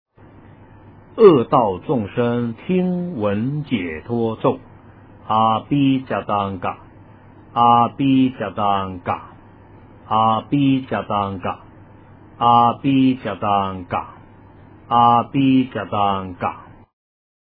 诵经
佛音 诵经 佛教音乐 返回列表 上一篇： 祈愿(点灯 文